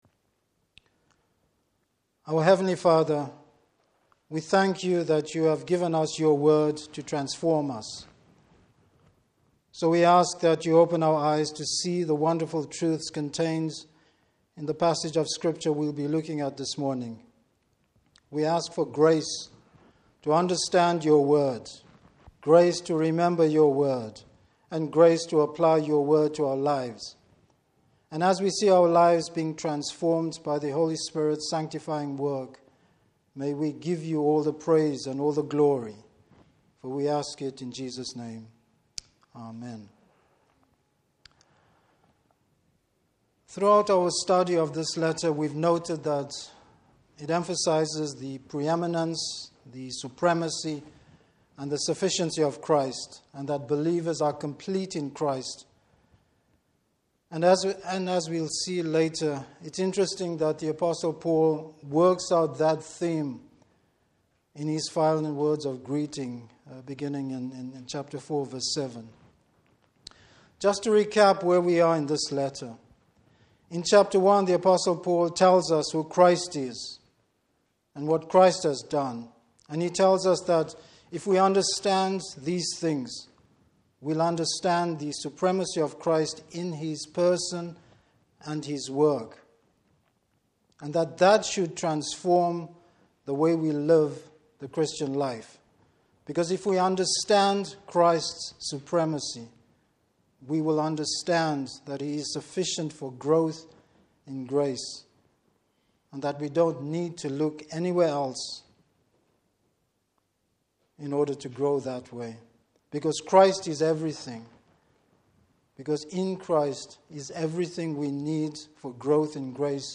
Passage: Colossians 4:7-18. Service Type: Morning Service Partnership in the Gospel.